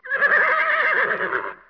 دانلود صدای حیوانات جنگلی 37 از ساعد نیوز با لینک مستقیم و کیفیت بالا
جلوه های صوتی